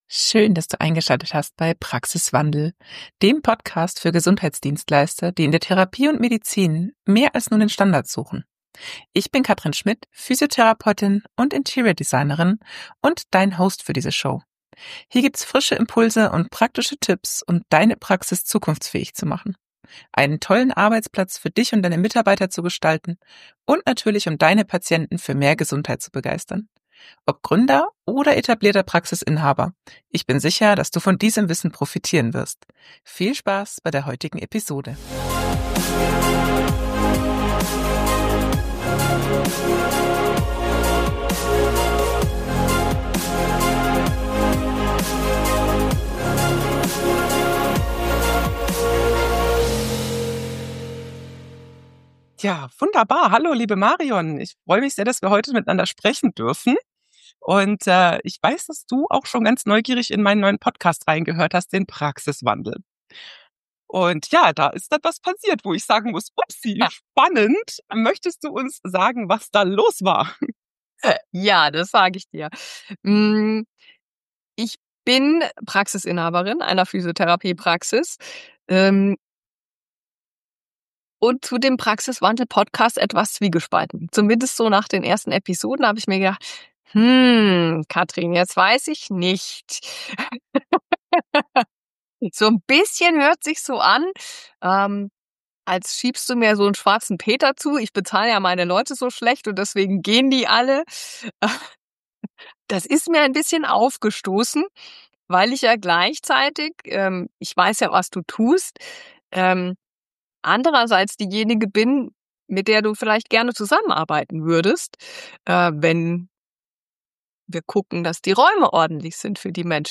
Sie ist Physiotherapeutin und Praxisinhaberin sowie Expertin im Thema Kommunikation.